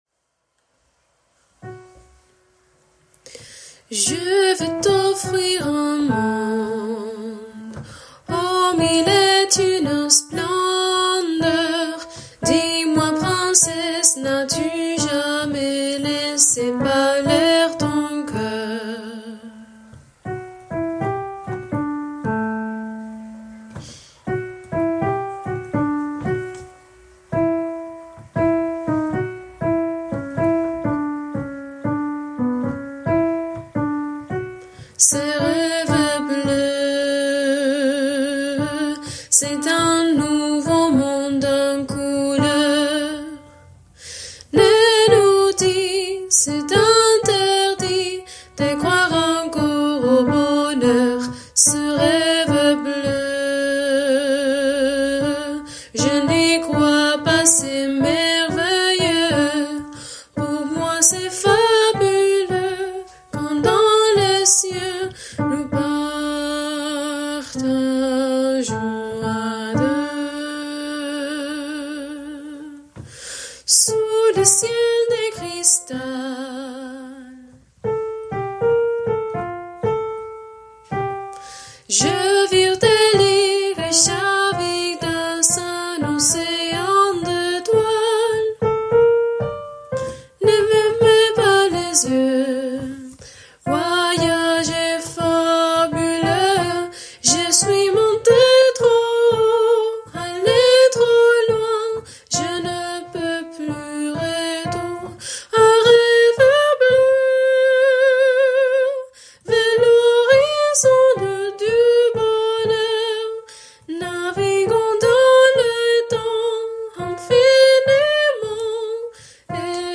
Hommes